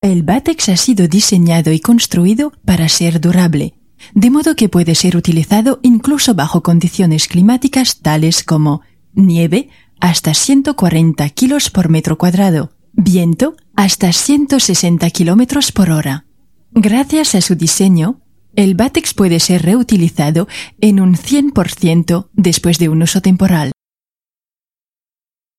Voix off
20 - 60 ans - Soprano